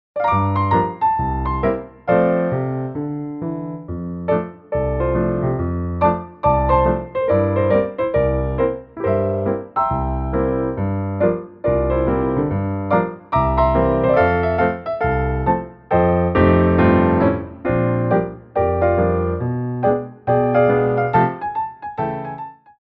Piano Arrangements of Popular Music
4/4 (8x8)